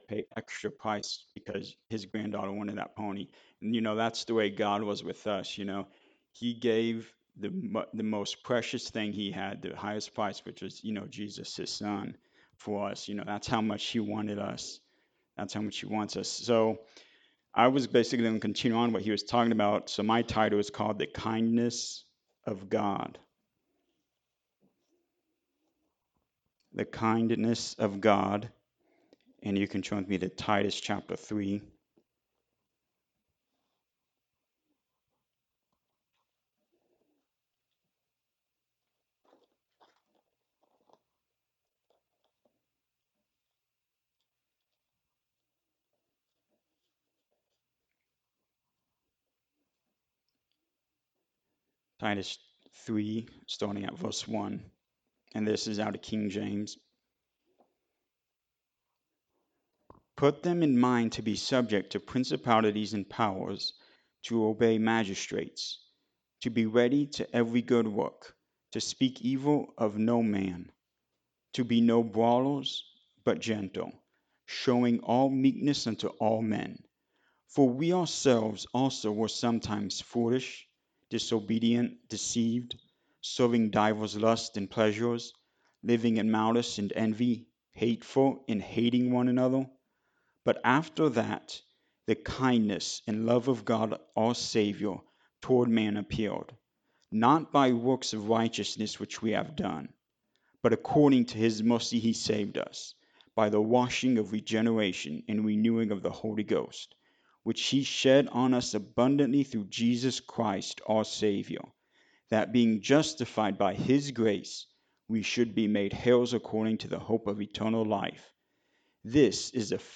In this final sermon about the fruit of the spirit, we are talking about self-control, or temperance.
Service Type: Sunday Morning Service